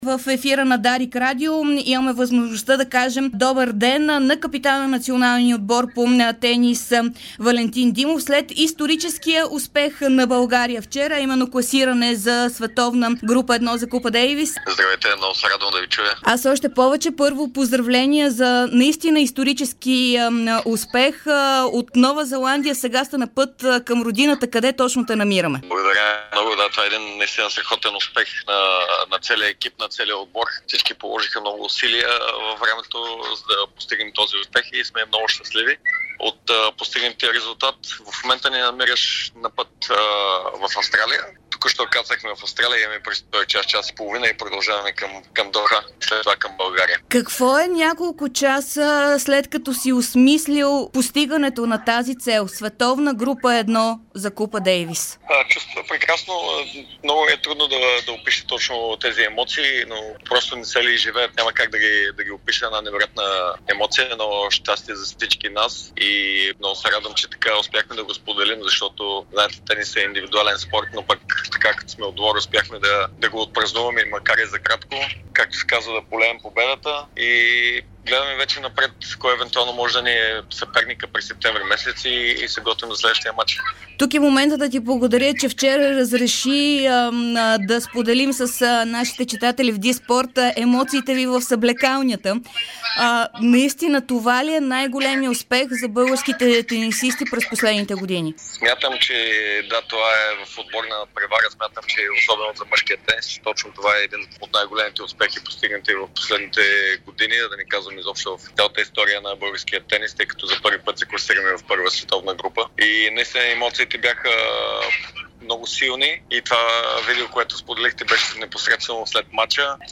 специално интервю